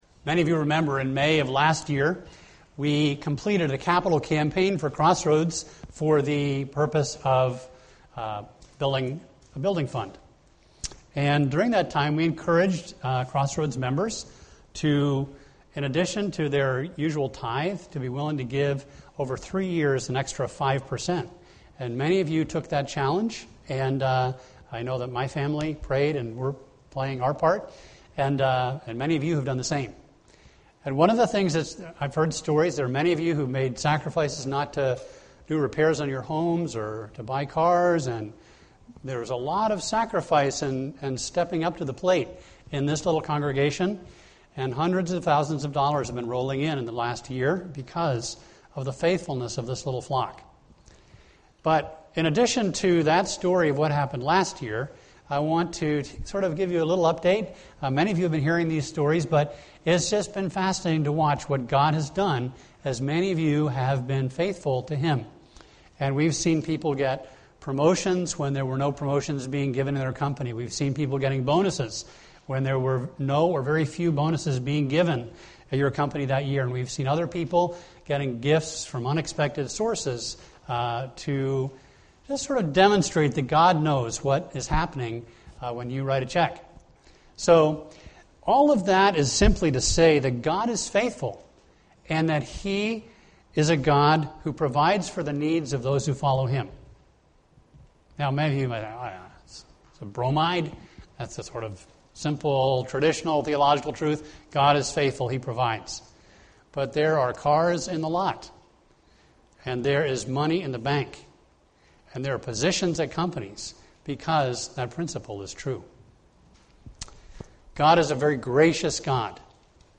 A message from the series "End Times."